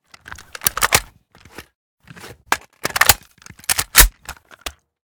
svu_reload_empty.ogg